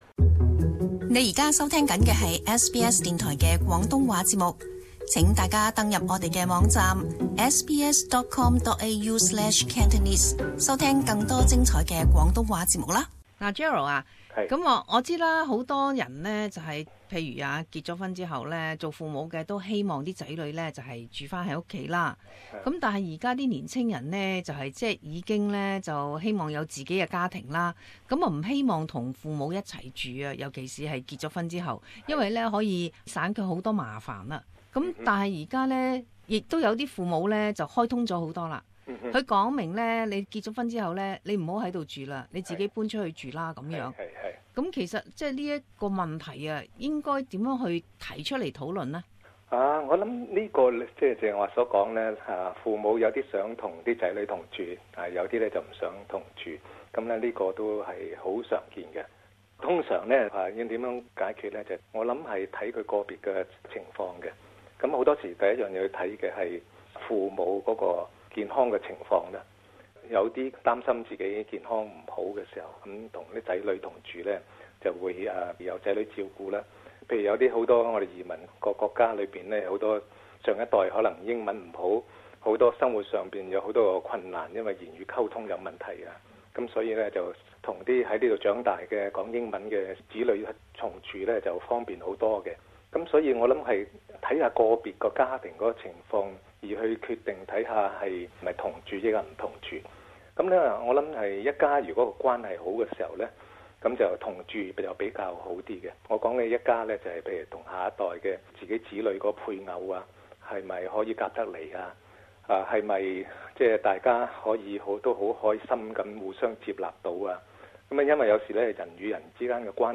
SBS广东话播客